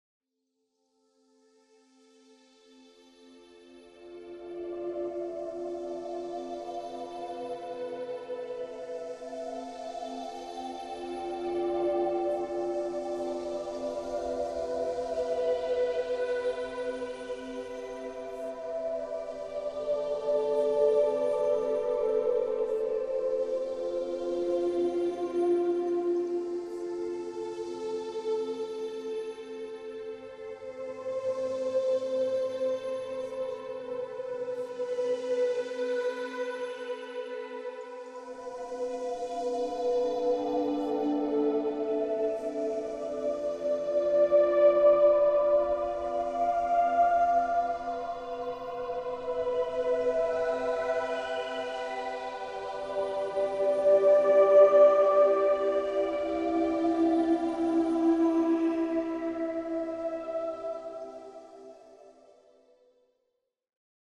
דוגמת מוזיקה בקלטת הראשונה – מוזיקה למדיטציה ולשינה:
הצלחה לעצמאים עם מוזיקה שקטה